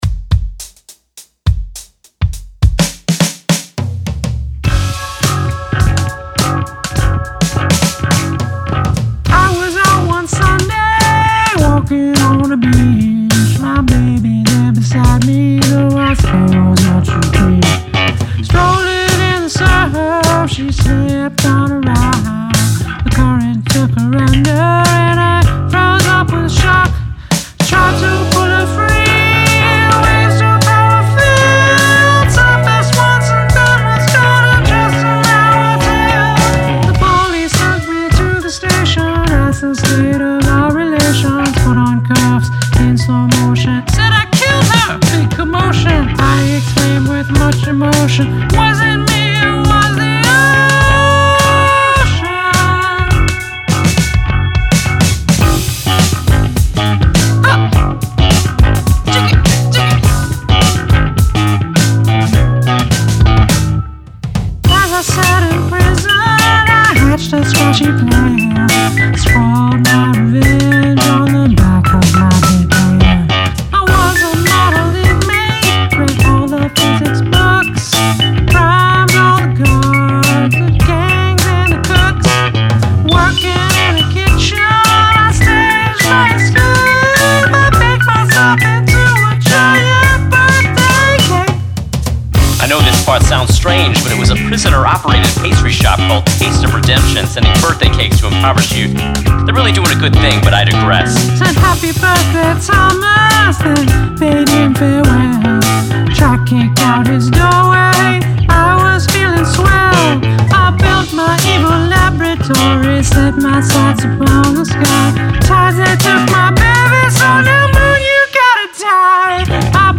Narrative song about a murder